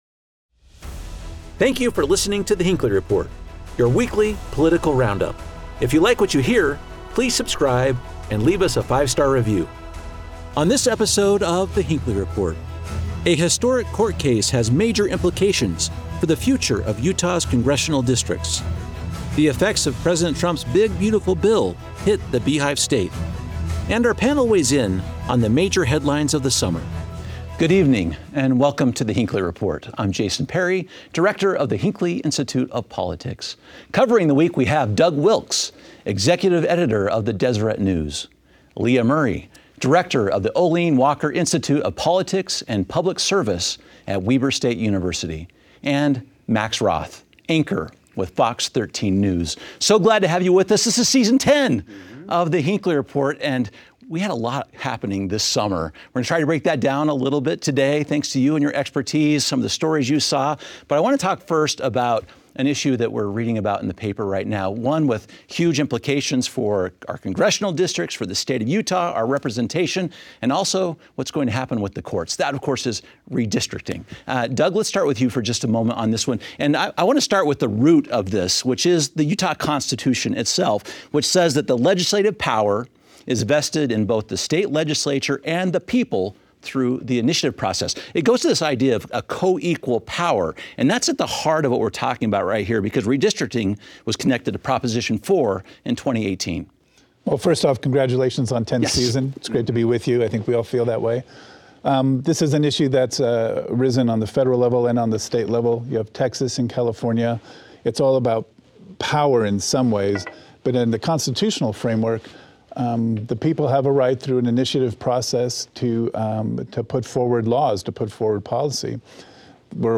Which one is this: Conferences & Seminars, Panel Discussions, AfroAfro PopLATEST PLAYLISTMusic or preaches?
Panel Discussions